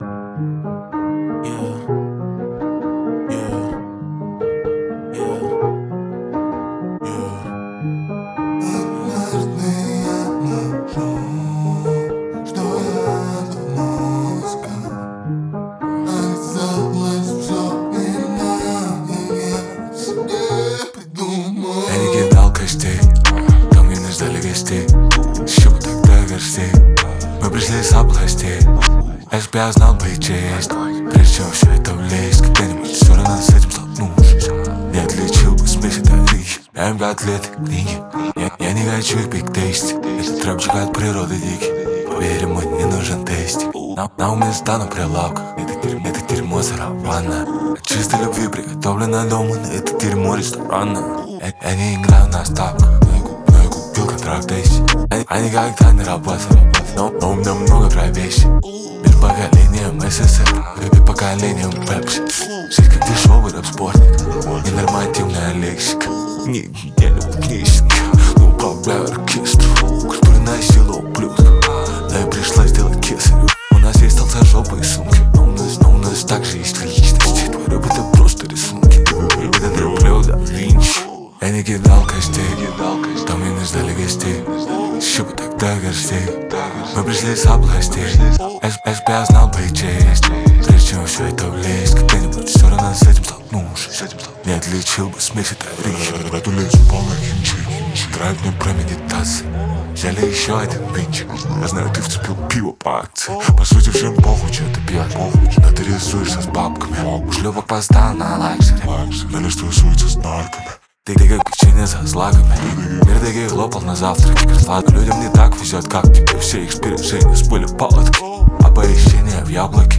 Ненормативная лексика